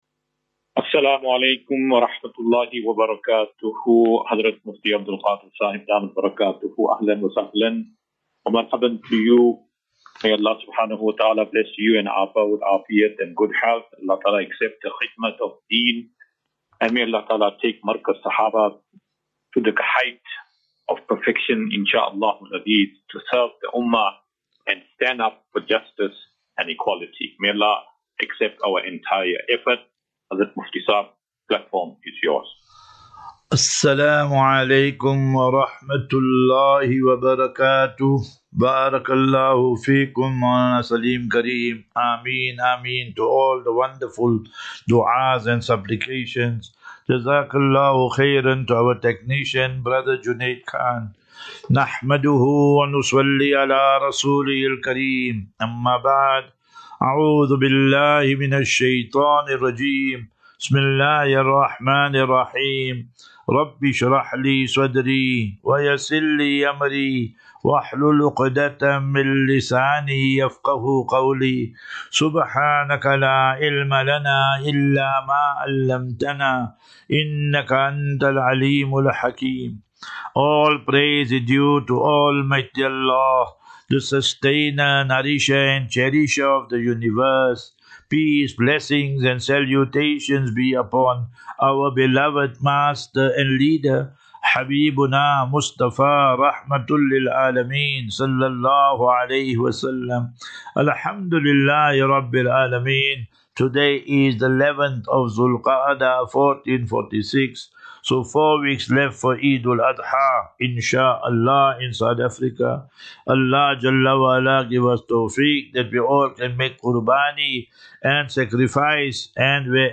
As Safinatu Ilal Jannah Naseeha and Q and A 10 May 10 May 2025.